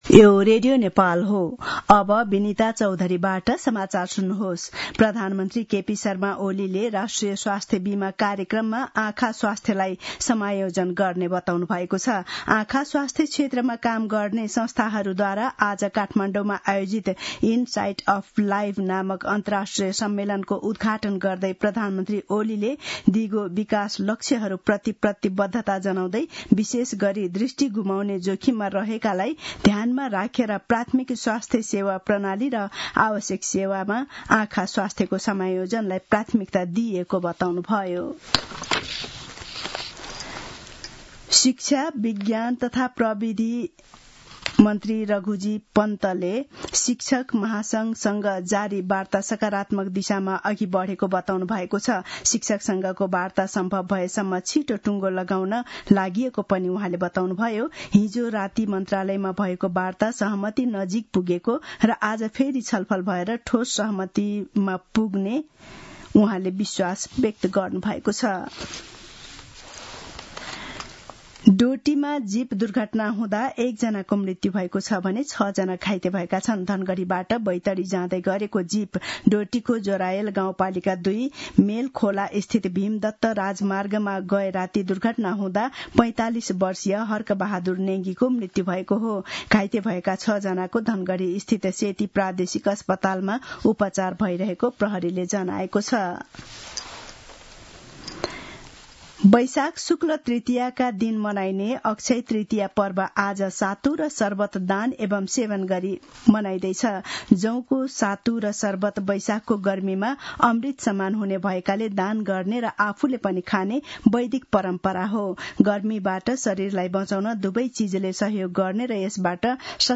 मध्यान्ह १२ बजेको नेपाली समाचार : १७ वैशाख , २०८२